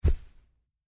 土路上的脚步声－偏低频－右声道2－YS070525.mp3
通用动作/01人物/01移动状态/土路/土路上的脚步声－偏低频－右声道2－YS070525.mp3
• 声道 立體聲 (2ch)